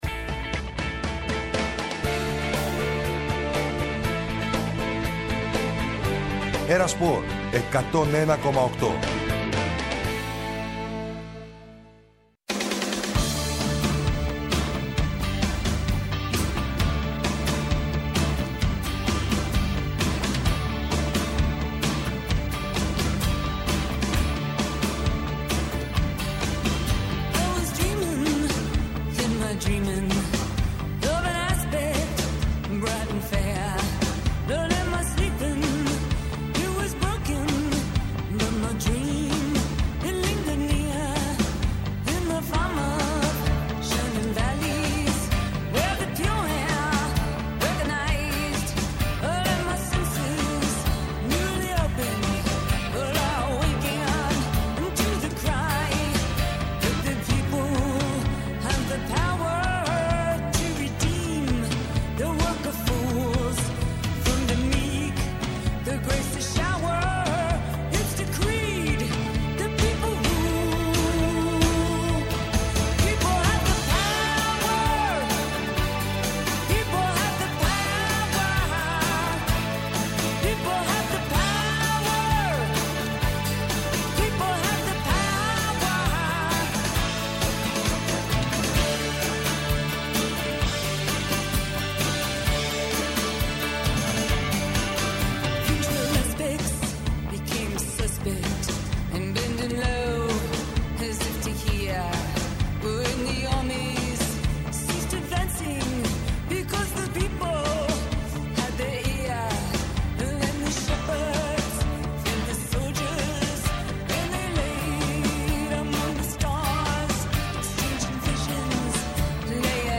Ρεπορτάζ και συνεντεύξεις, με βάση το ομότιτλο αθλητικό site της ΕΡΤ.